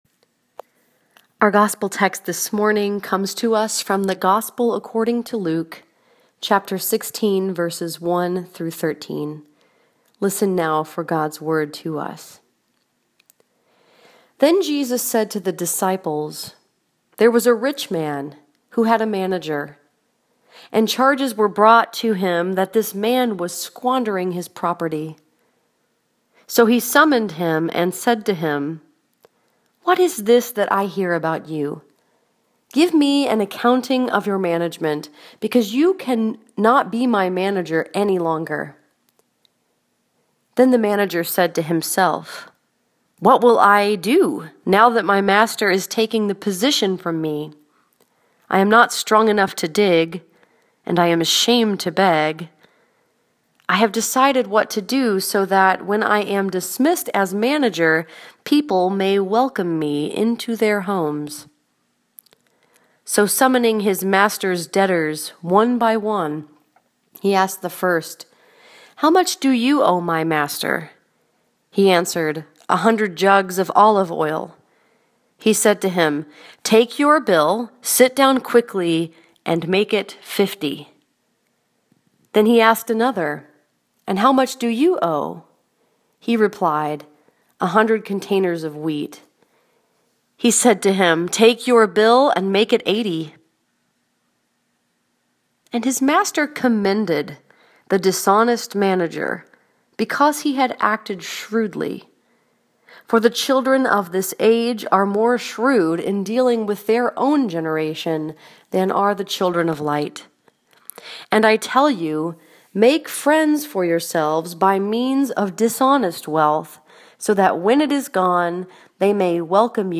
This sermon was preached at New Life Presbyterian Church in Sterling Heights, Michigan and was focused upon Luke 16:1-13.